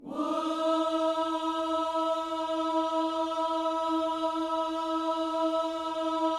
WHOO E 4A.wav